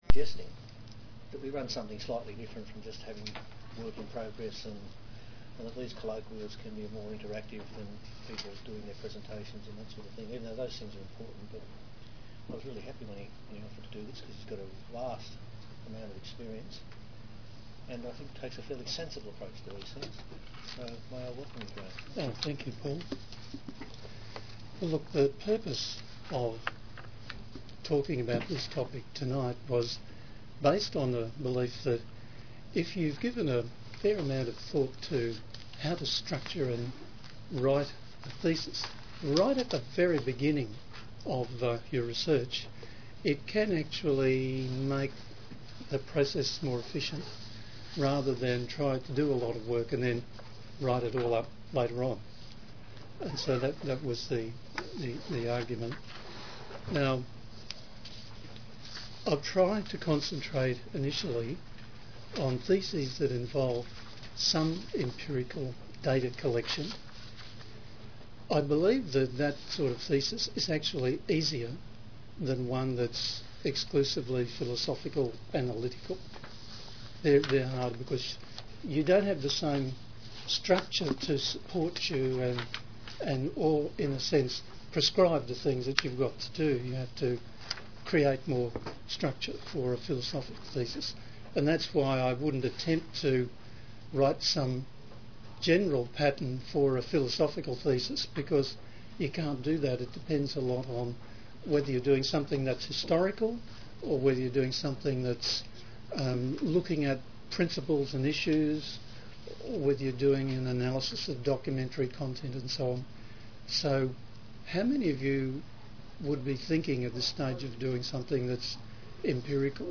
Seminar for research students and supervisors on how to structure doctoral research and write up a doctoral thesis
SeminarDoctoralThesisDevelopment.mp3